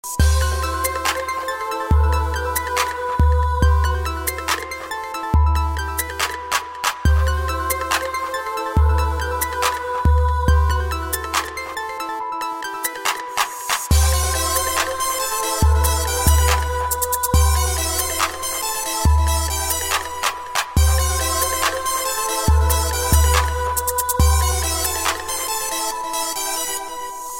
• Качество: 128, Stereo
Electronic
без слов
Trance
клубные